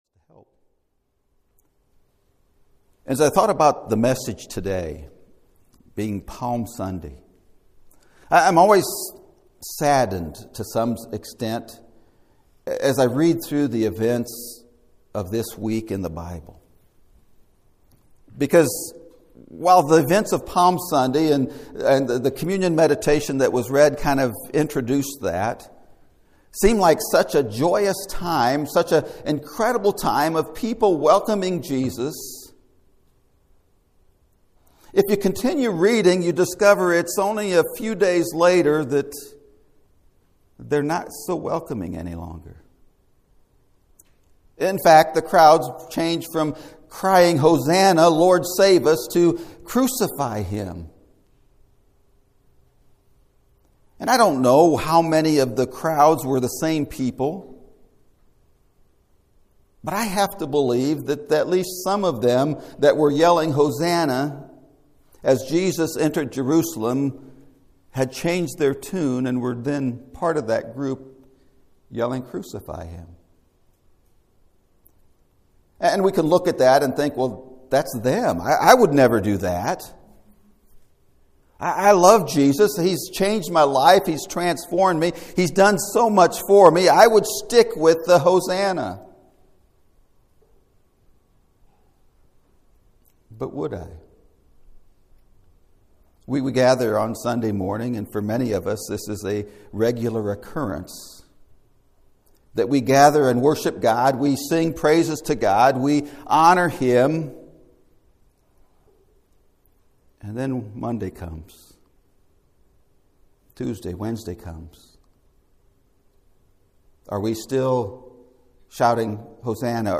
An Offering of PALMS! (Sermon Audio)